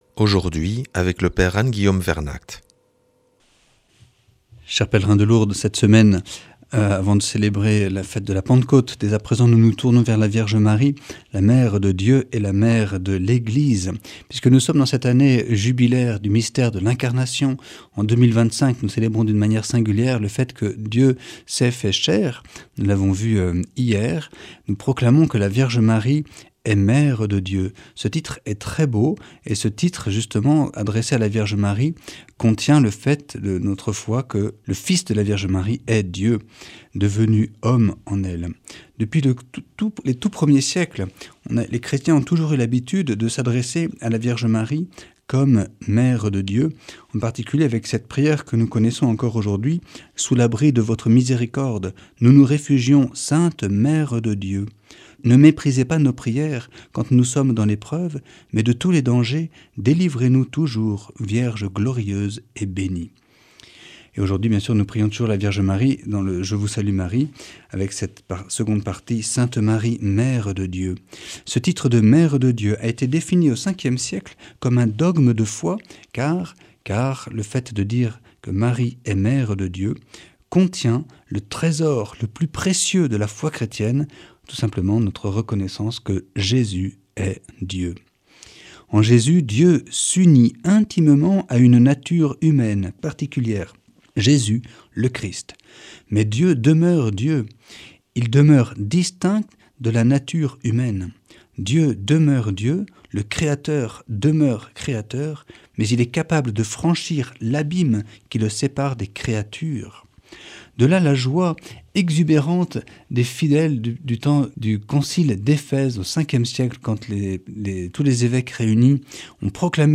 Enseignement Marial du 03 juin